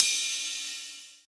D2 RIDE-06-R.wav